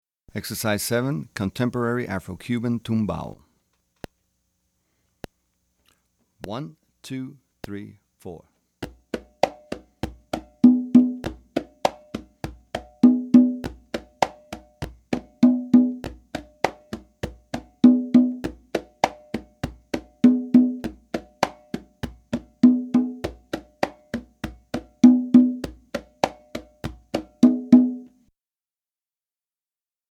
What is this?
Voicing: Percussion